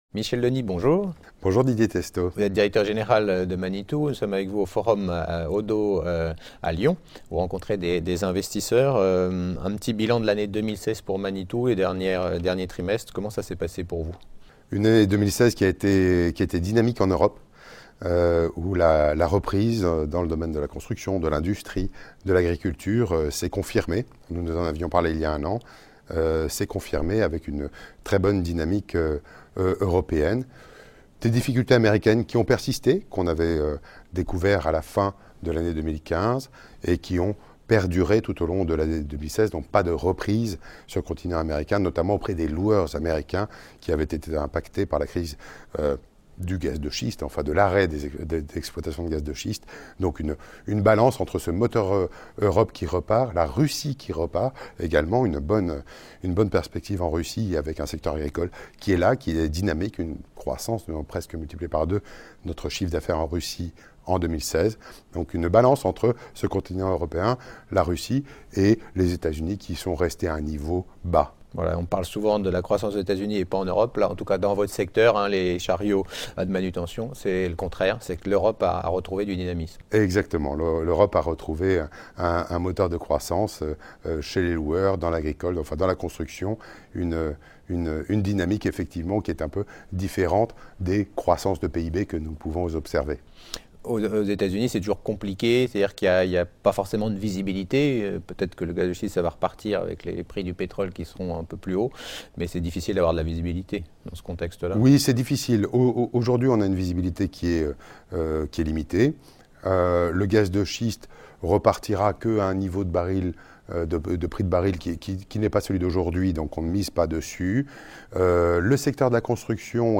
Rencontres avec les dirigeants au Oddo Forum de Lyon du 5 et 6 janvier 2017